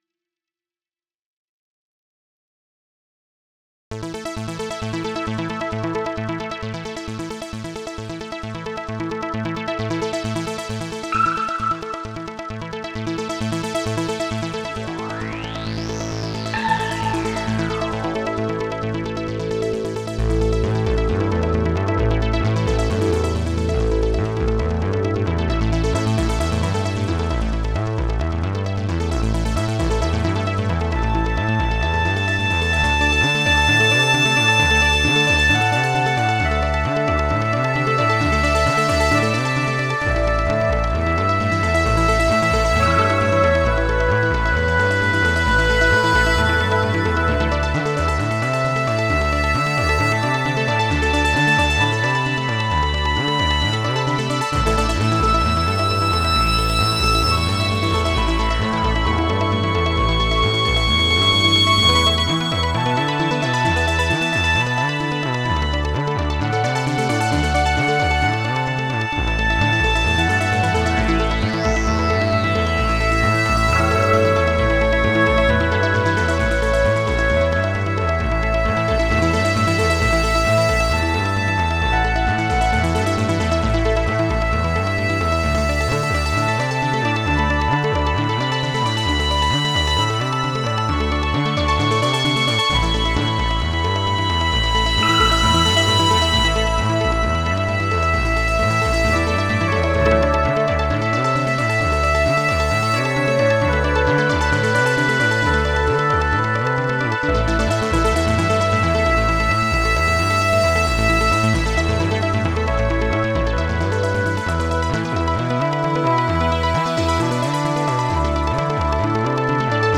It was made using mostly 3-OSC and LB302, but also a track or two with Synthv1, with a bit of chorus, delay and reverb to taste.